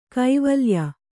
♪ kaivalya